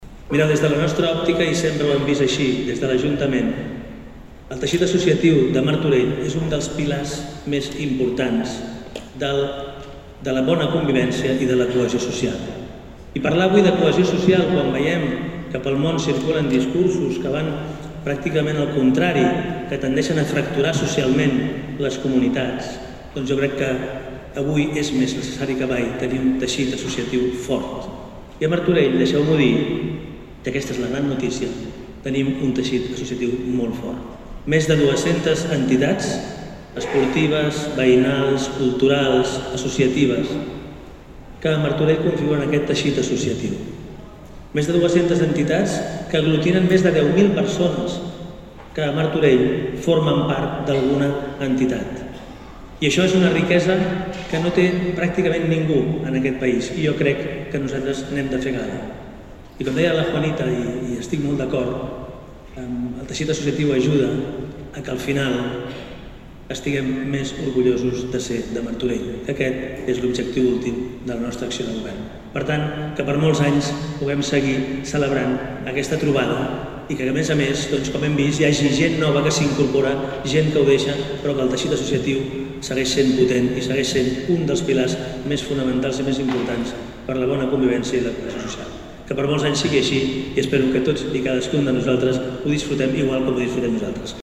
La 29a Trobada d’Entitats ha reconegut l’associacionisme a Martorell aquest vespre a El Progrés, en una gala amb prop de 400 assistents.
Xavier Fonollosa, alcalde de Martorell